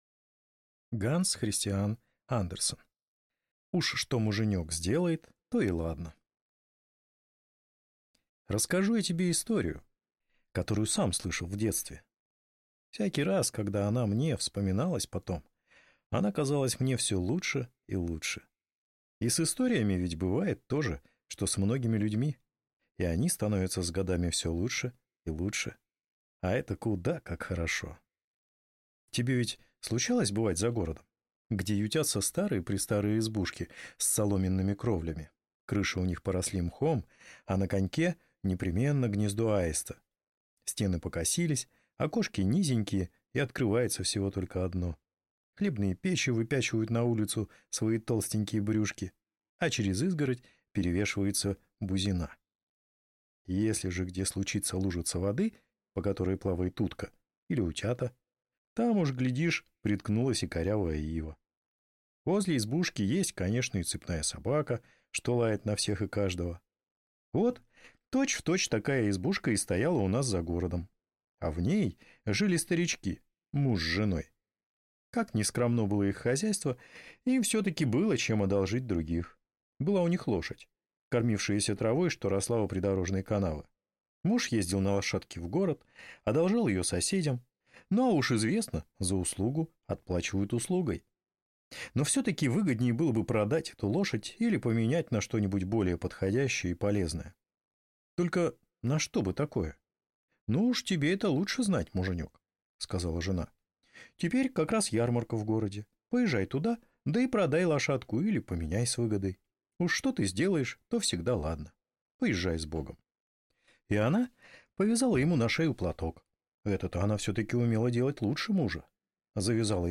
Аудиокнига Уж что муженек сделает, то и ладно | Библиотека аудиокниг